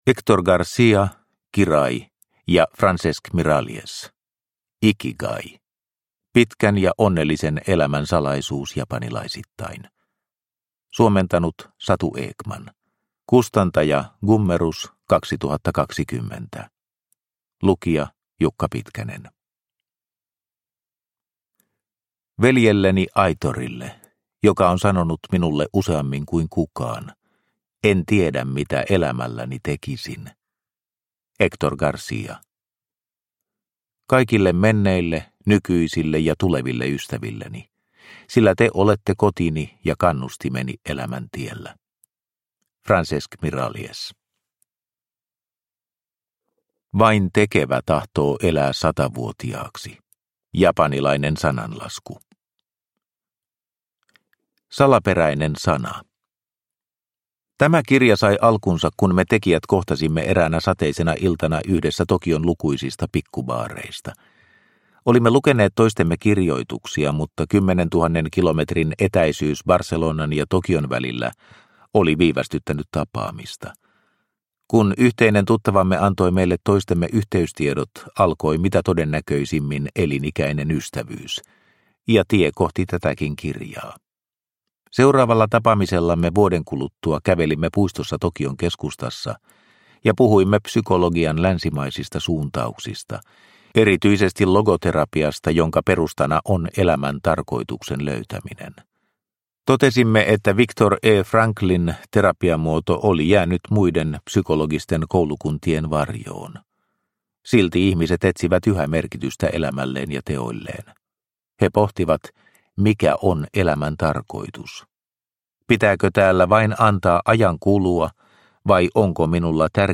Ikigai – Ljudbok – Laddas ner